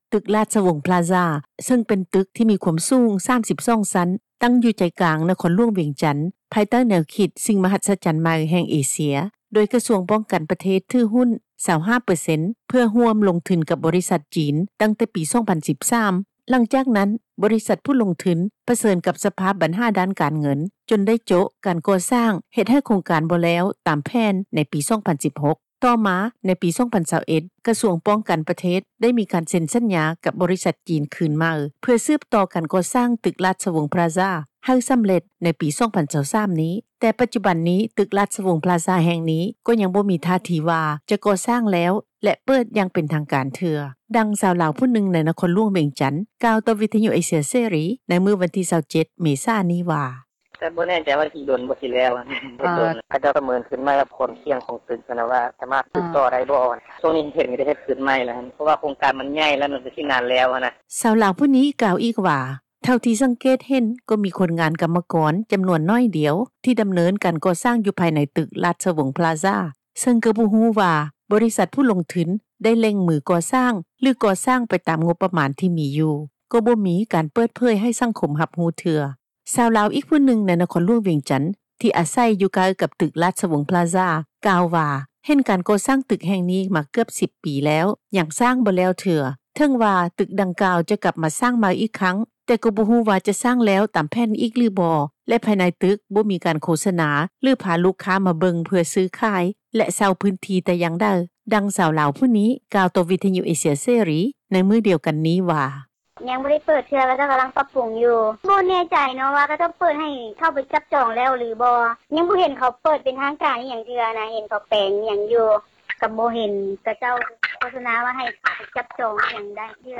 ດັ່ງ ຊາວລາວຜູ້ນຶ່ງ ໃນນະຄອນຫລວງວຽງຈັນ ກ່າວຕໍ່ວິທຍຸເອເຊັຽ ເສຣີ ໃນມື້ວັນທີ 27 ເມສາ ນີ້ວ່າ: